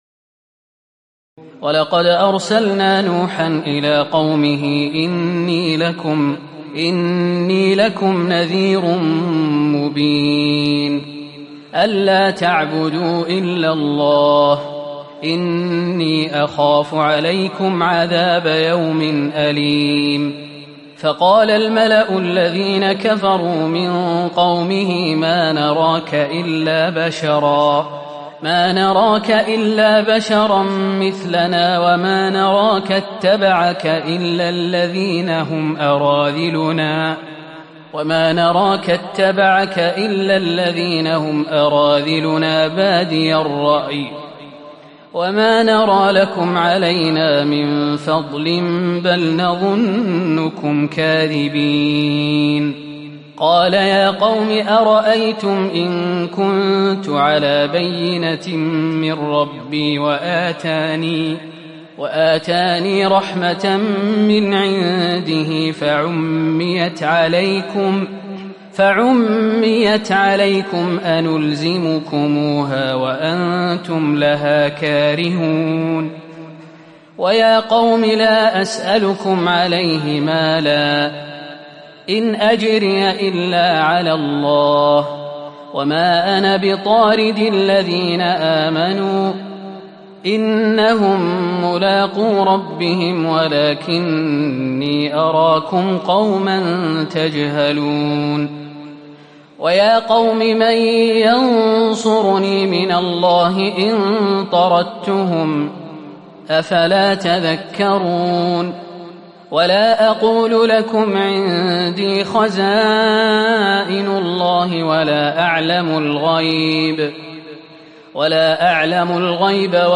تراويح الليلة الثانية عشر رمضان 1438هـ من سورة هود (25-123) Taraweeh 12 st night Ramadan 1438H from Surah Hud > تراويح الحرم النبوي عام 1438 🕌 > التراويح - تلاوات الحرمين